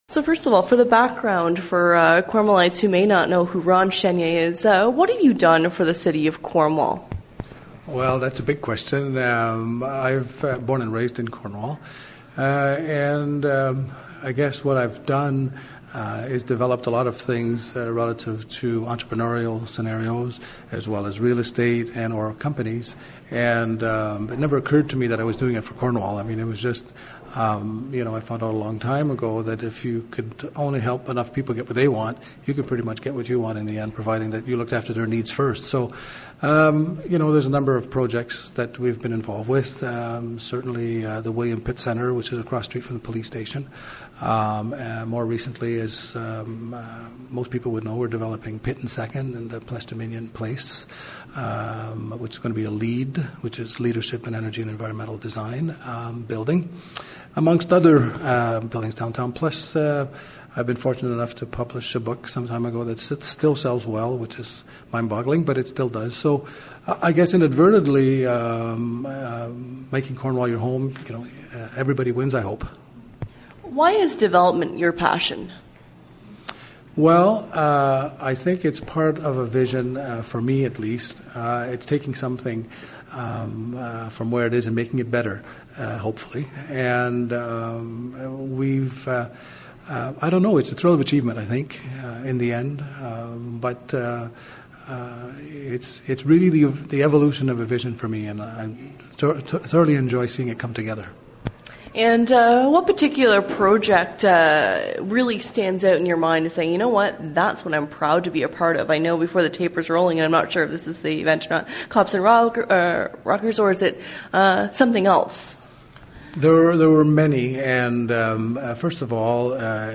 Download Corus Interview (8MB MP3) | Parable of the Trapeze Story (70KB PDF)